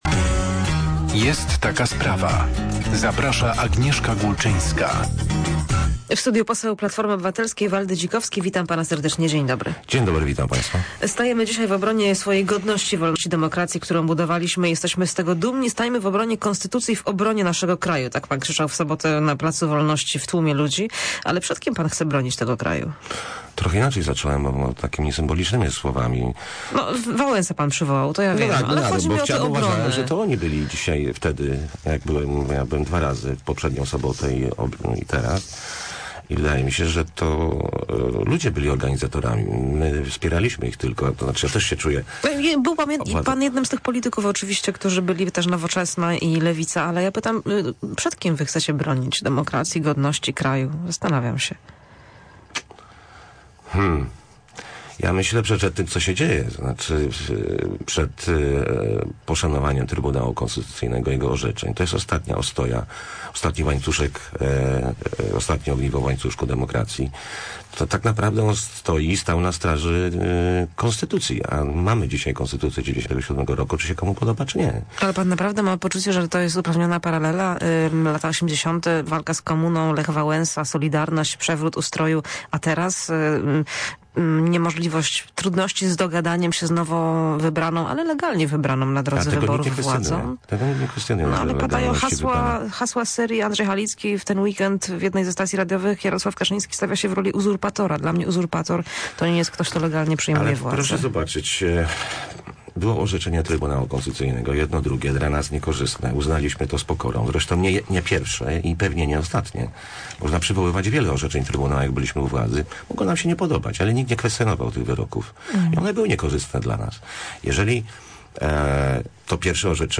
Poseł był gościem porannej rozmowy Radia Merkury "Jest taka sprawa".